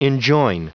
Prononciation du mot enjoin en anglais (fichier audio)
Prononciation du mot : enjoin